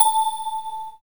5205L SYNBEL.wav